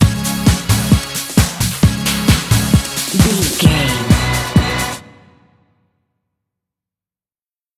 Epic / Action
Fast paced
Ionian/Major
Fast
synthesiser
drum machine
80s